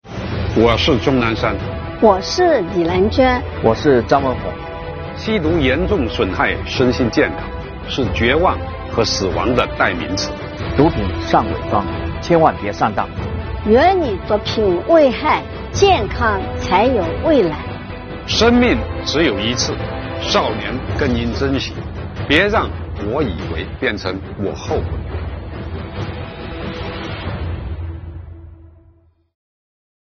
钟南山、李兰娟院士和
上海华山医院感染科主任张文宏
出镜录制了禁毒公益宣传片